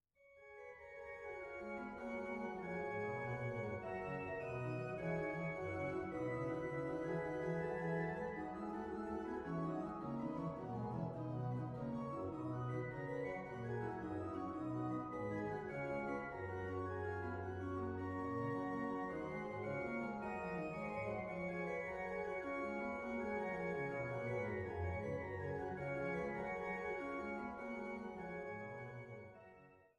Schlosskirche Altenburg
Cembalo